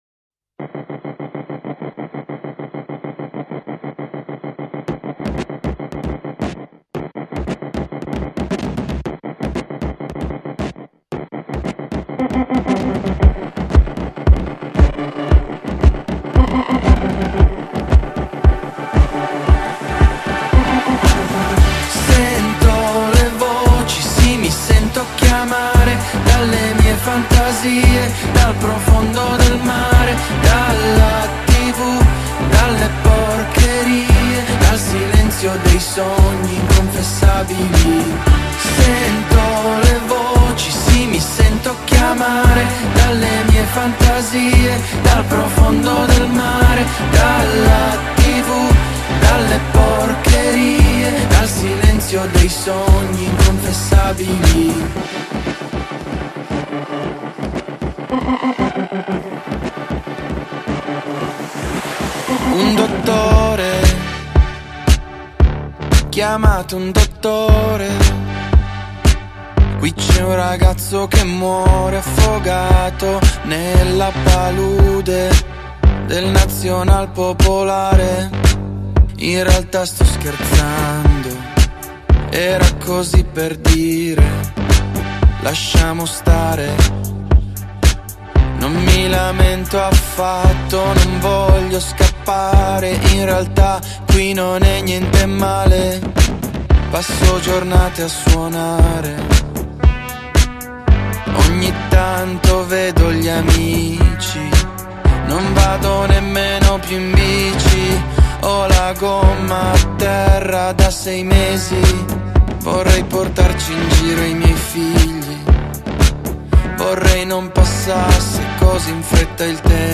un disco cantato in italiano
dalle fondamenta techno e house